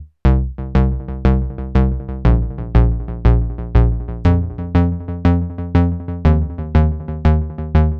TSNRG2 Bassline 013.wav